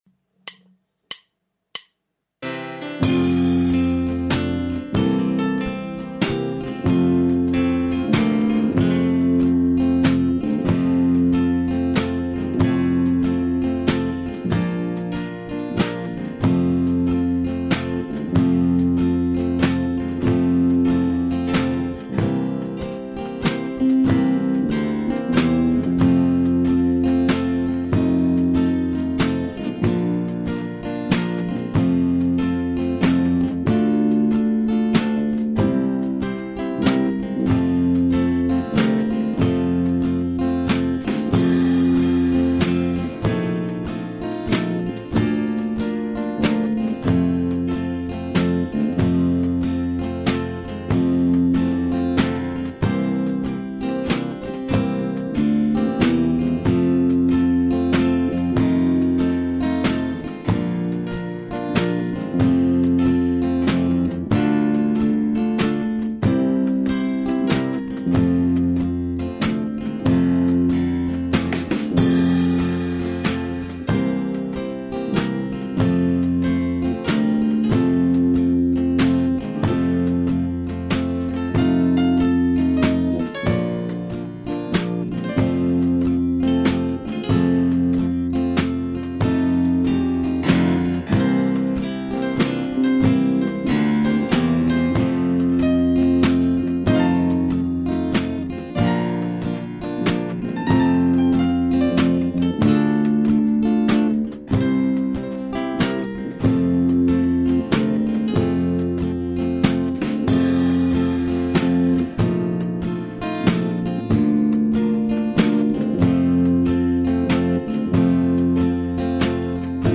Amazing grace (минус в F)
Губная гармошка С (тональность Фа мажор, 12-я позиция)